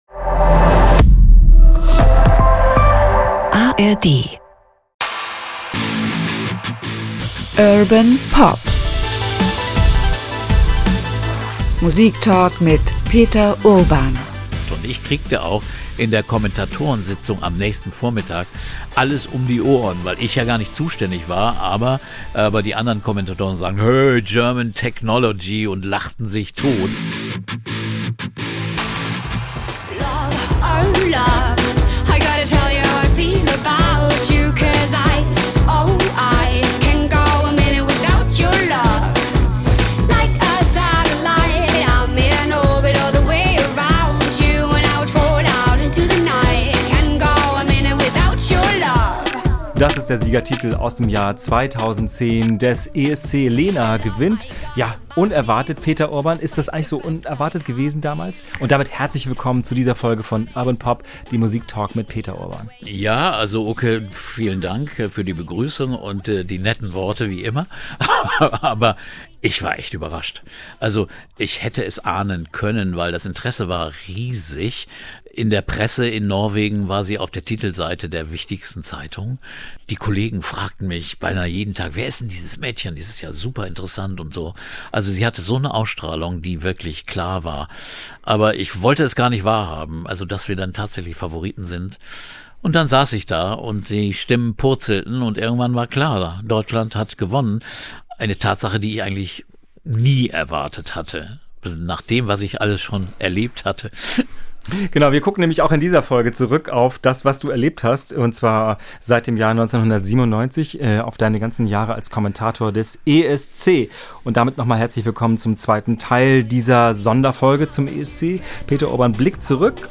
25 Jahre ESC - Peter Urbans persönlicher Rückblick (2/2) ~ Urban Pop - Musiktalk mit Peter Urban Podcast